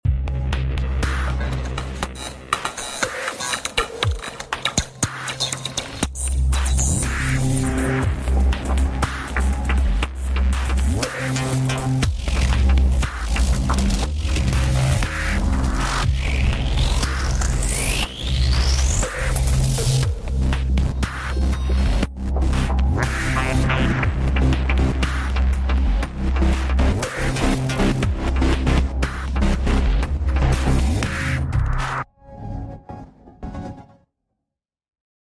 waves of me synth exp. sound effects free download